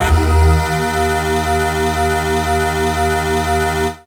55bg-syn08-d#2.wav